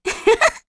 Hanus-Vox_Happy1.wav